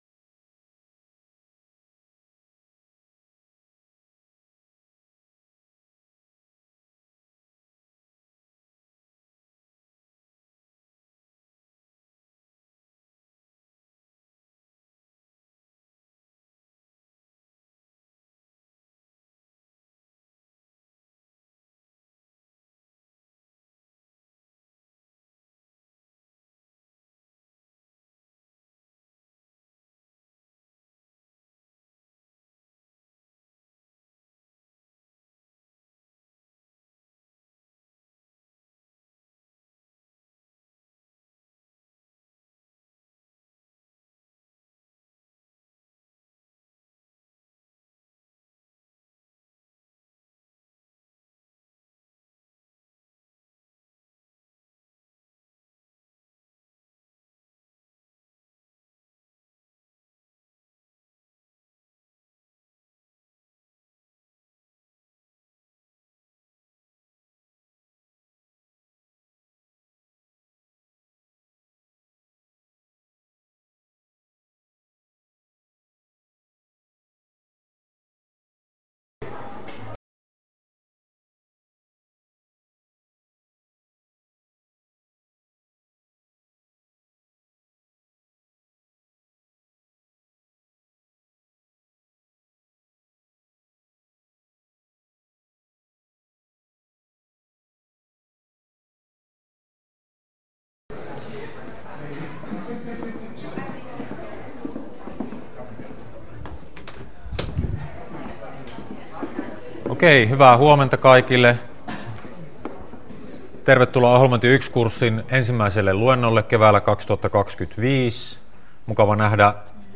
Luento 1 — Moniviestin